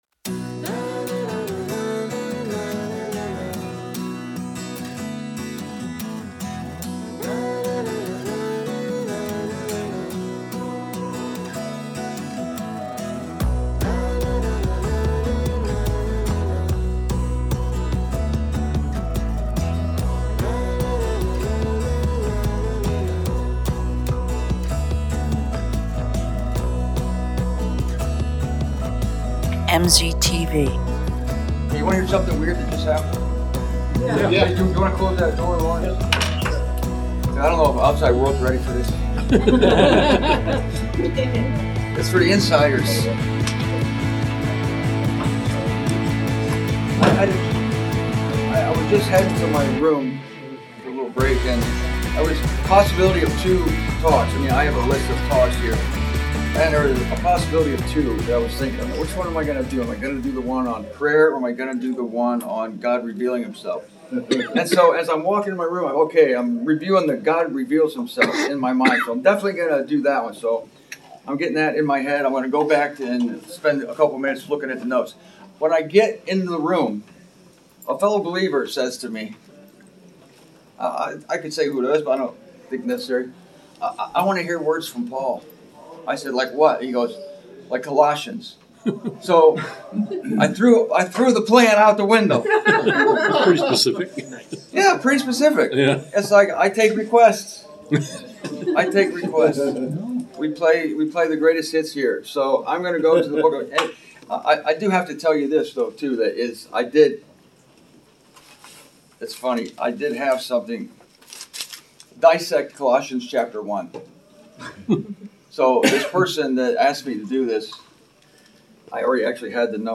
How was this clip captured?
From the Phoenix, Arizona conference; May 6, 2023.